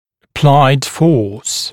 [ə’plaɪd fɔːs][э’плайд фо:с]приложенное усилие